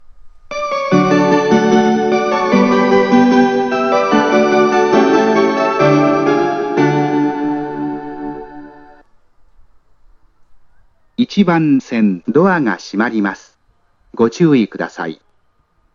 接近放送 「森の妖精」です。
●音質：良